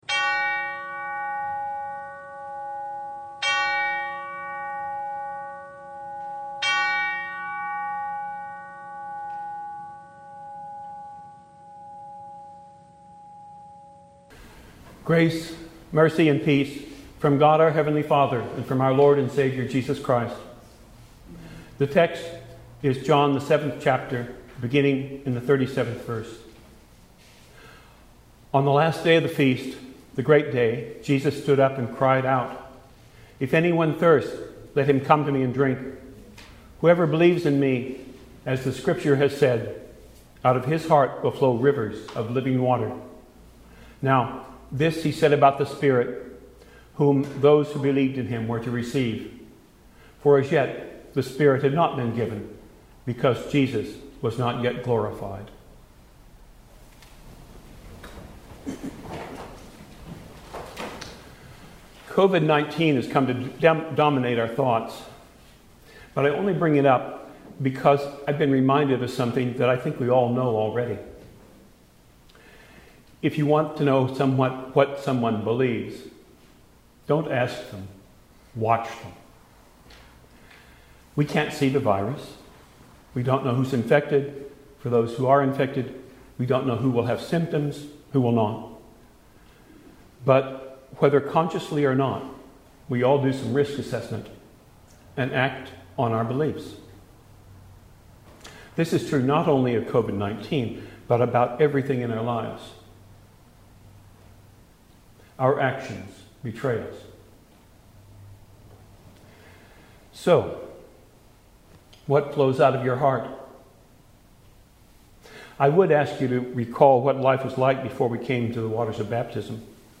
Pentecost, Christ Lutheran Church, Troy, NH